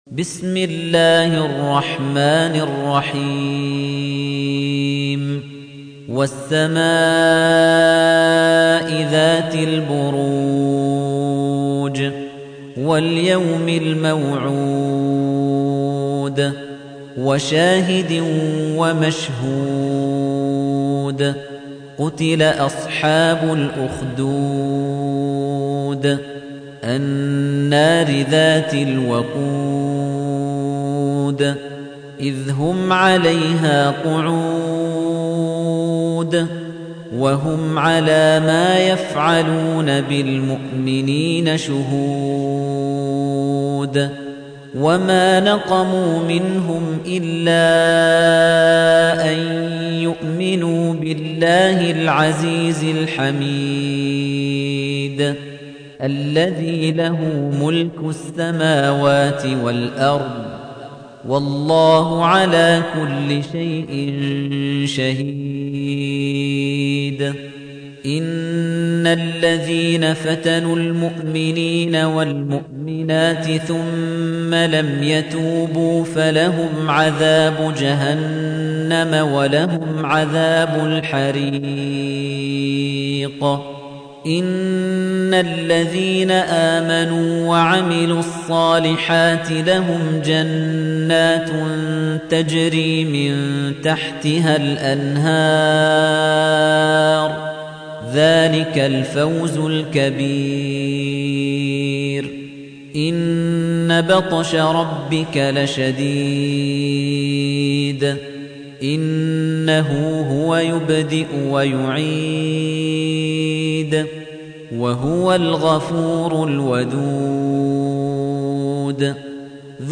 تحميل : 85. سورة البروج / القارئ خليفة الطنيجي / القرآن الكريم / موقع يا حسين